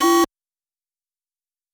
PhishingAlert.wav